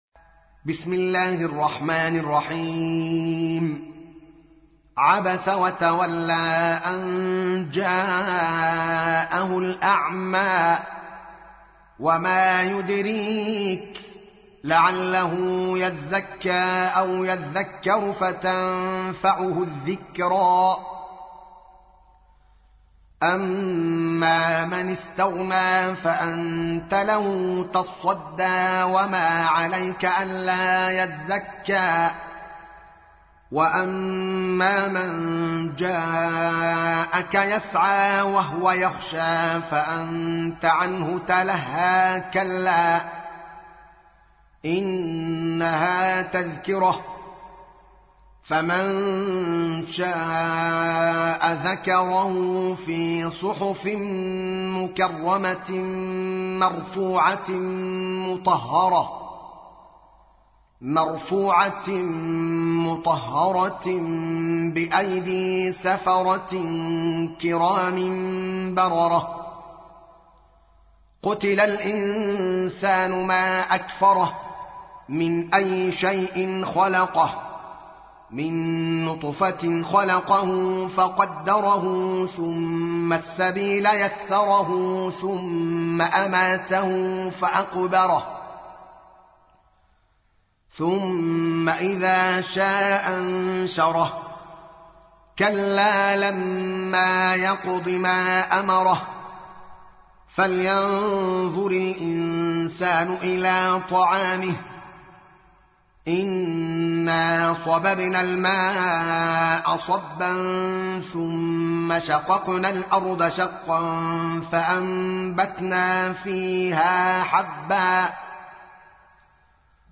تحميل : 80. سورة عبس / القارئ الدوكالي محمد العالم / القرآن الكريم / موقع يا حسين